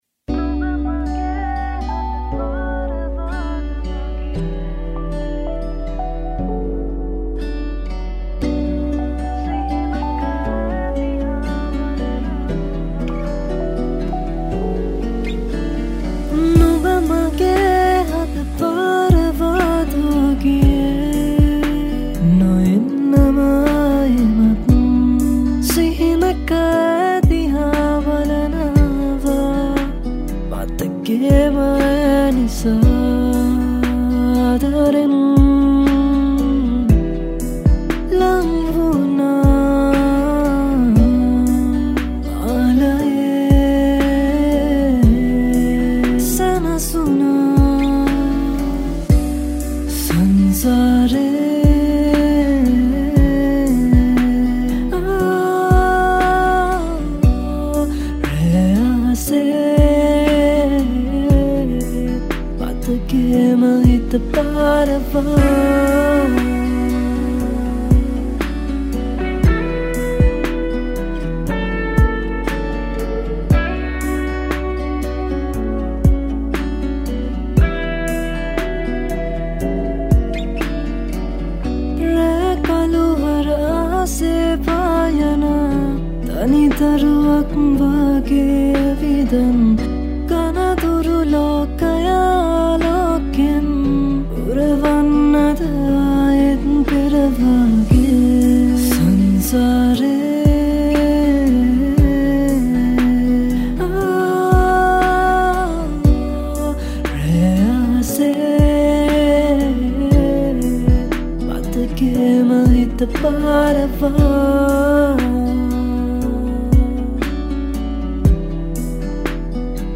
Lead guitarist